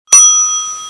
bouton_ding